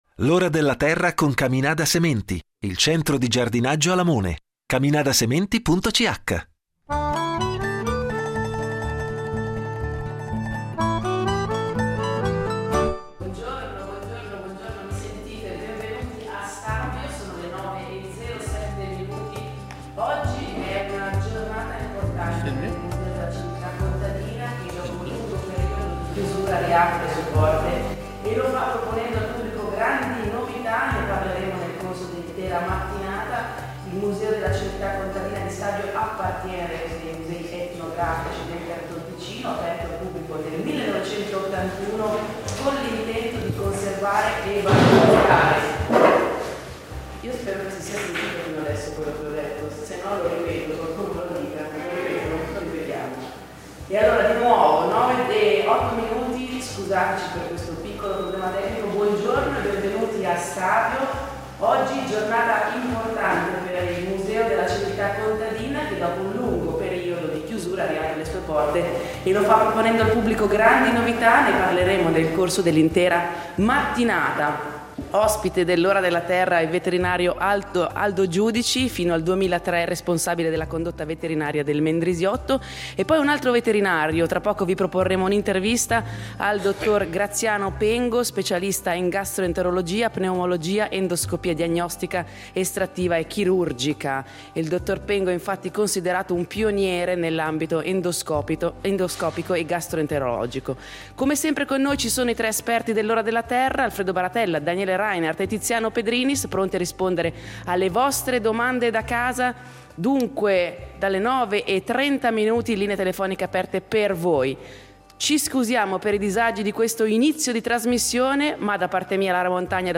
Naturalmente non mancheranno gli esperti del programma, pronti a rispondere alle domande del pubblico da casa.